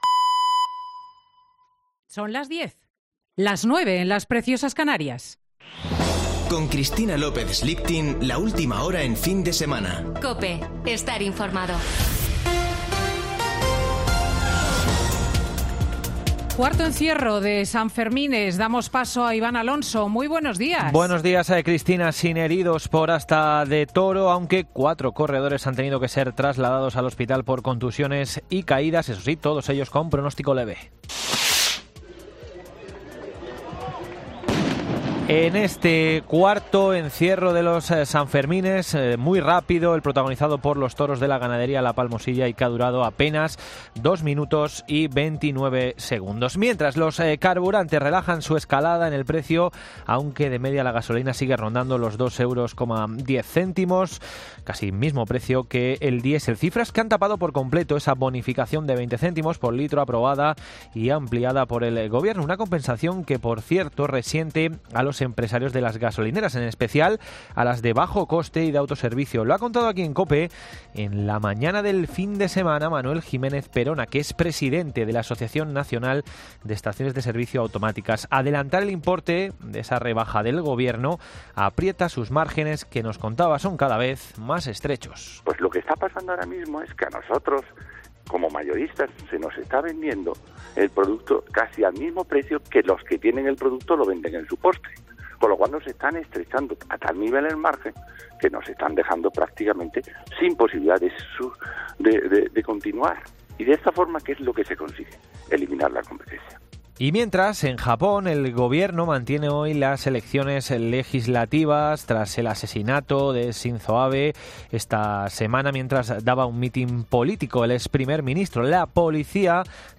Boletín de noticias de COPE del 10 de julio de 2022 a las 10:00 horas